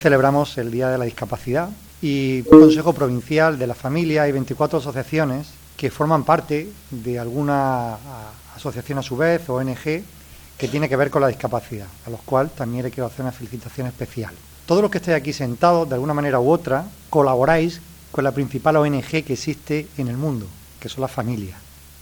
El presidente de Diputación ha inaugurado el último pleno del año dando la bienvenida a cinco nuevas asociaciones y anunciando que las ayudas para 2025 vuelven a crecer un 20% más
03-12_consejo_familia_dia_discapacidad_presidente.mp3